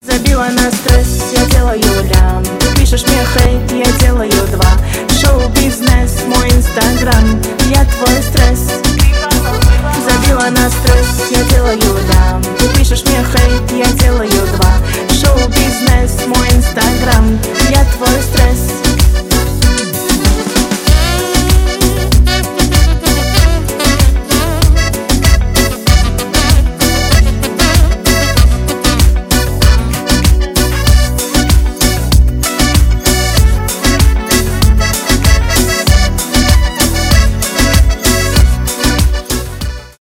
позитивные , аккордеон
танцевальные , шансон